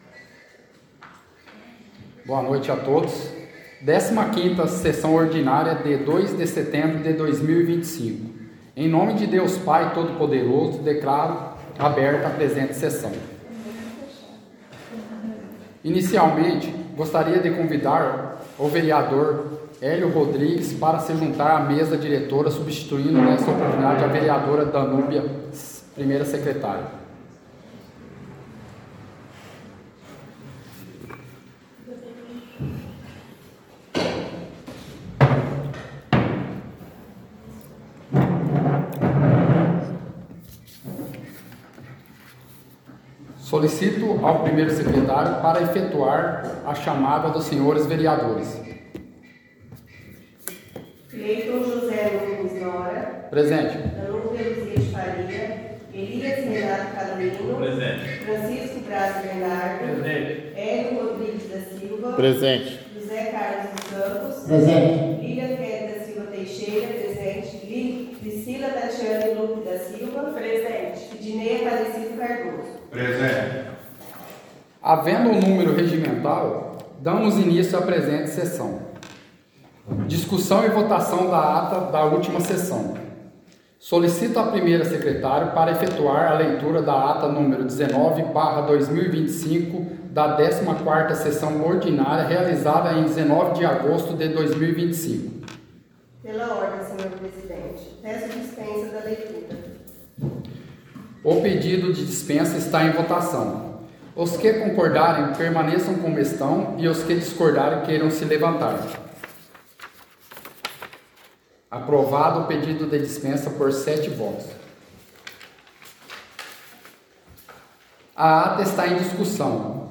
Áudio da 15ª Sessão Ordinária – 02/09/2025